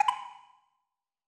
arcade-coin.mp3